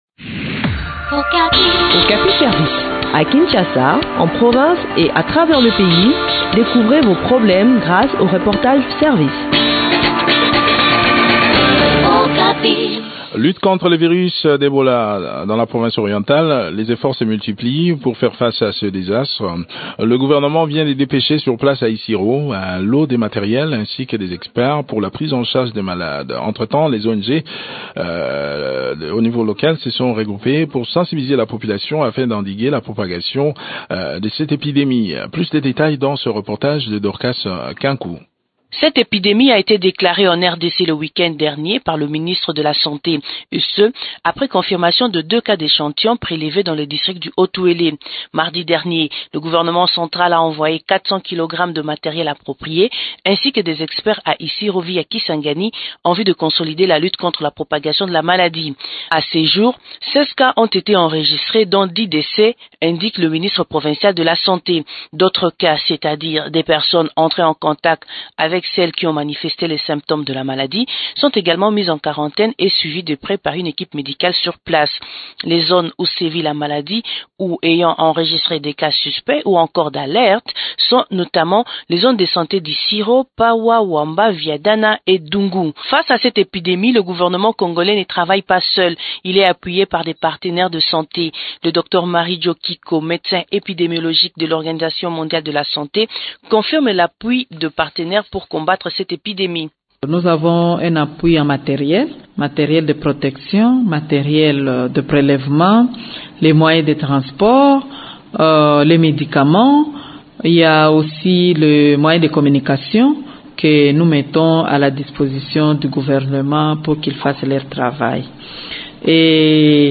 Le point de la situation sur le terrain dans cet entretien
Dr Angèle Uvon, ministre provincial de la santé